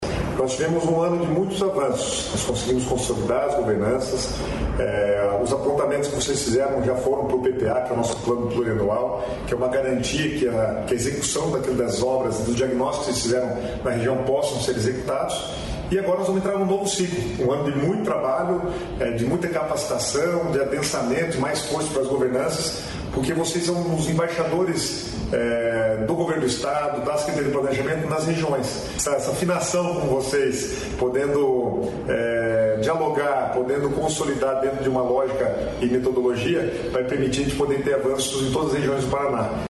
Sonora do secretário estadual do Planejamento, Guto Silva, sobre o programa Paraná Produtivo